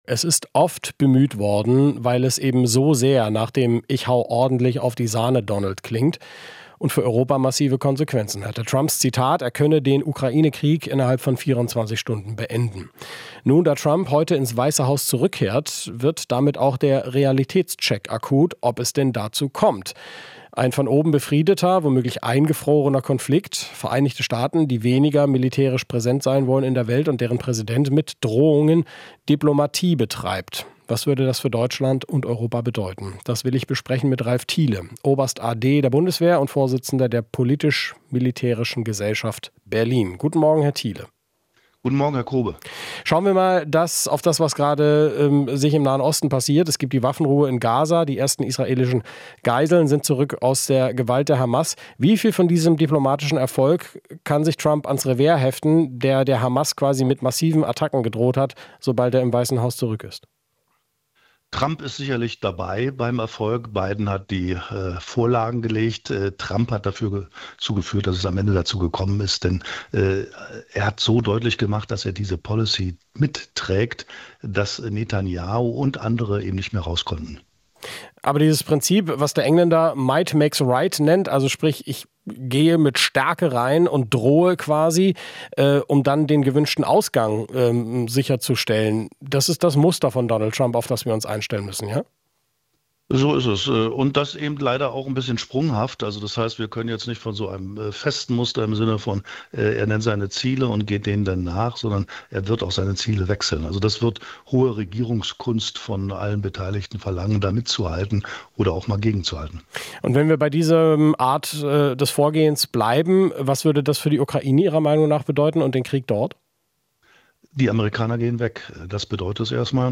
Interview - Unter Trump: Militärexperte erwartet Rückzug aus der Ukraine